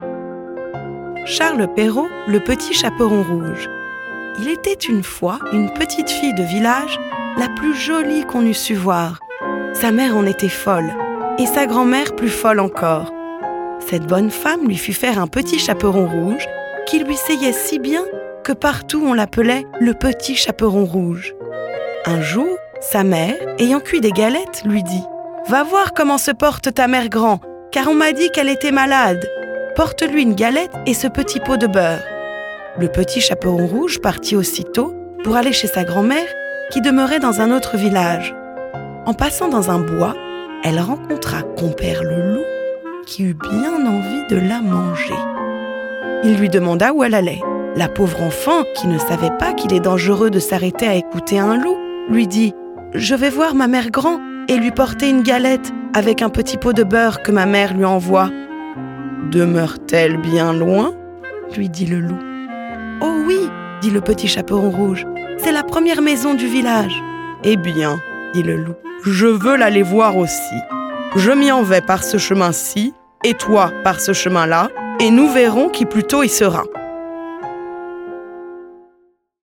FRENCH Experienced young, husky, engaging, warm VO | Rhubarb Voices